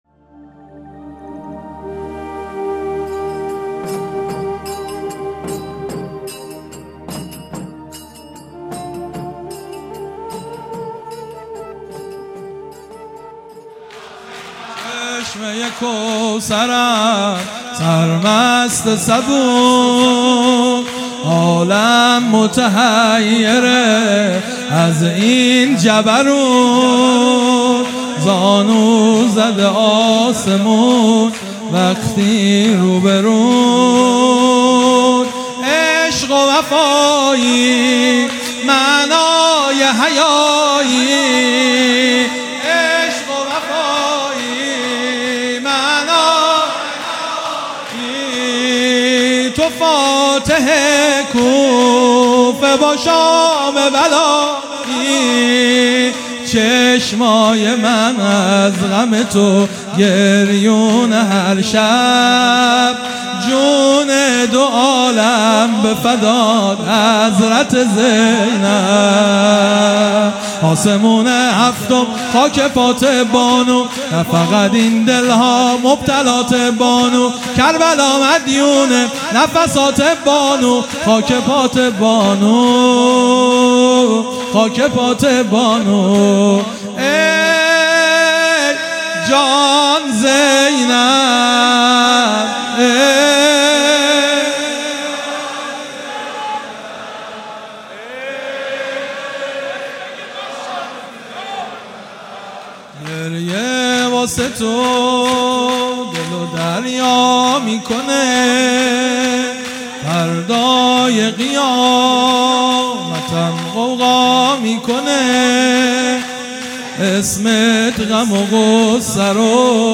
مداحی شور
شب چهارم محرم 1401